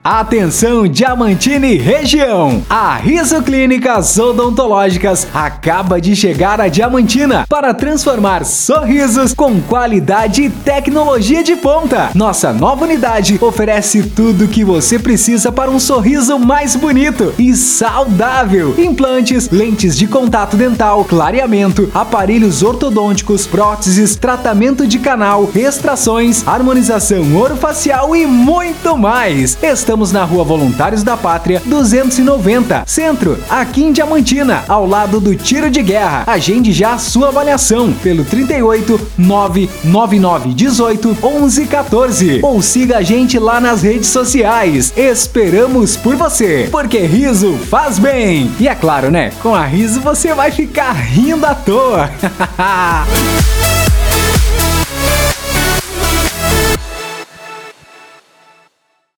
Animada: